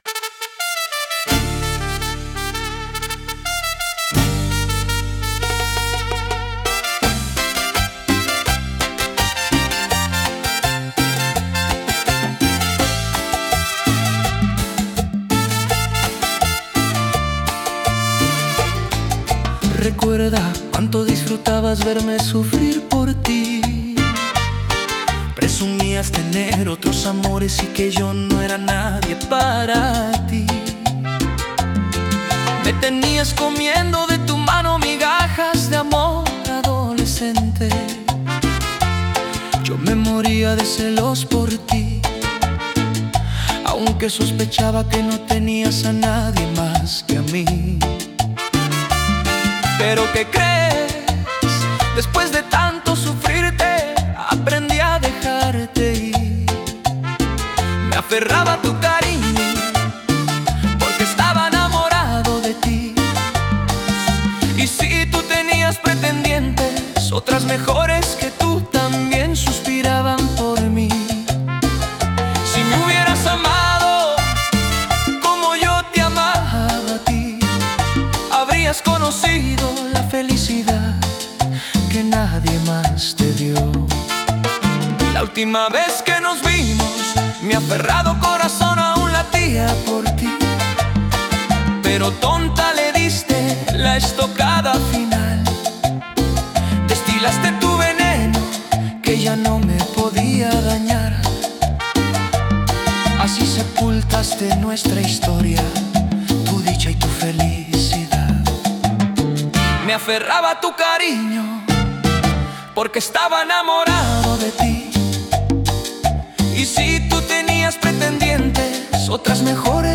Genre Cumbia